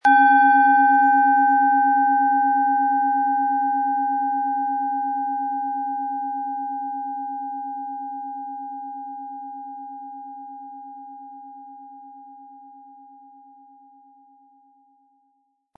Die Schale mit Biorhythmus Körper, ist eine in uralter Tradition von Hand getriebene Planetenklangschale.Weitergegebenes Know-how in kleinen Manufakturen, die seit Jahrhunderten Klangschalen herstellen, machen diese Klangschalen so unvergleichlich.
• Mittlerer Ton: Biorhythmus Geist
Um den Originalton der Schale anzuhören, gehen Sie bitte zu unserer Klangaufnahme unter dem Produktbild.
Der passende Schlegel ist umsonst dabei, er lässt die Schale voll und harmonisch tönen.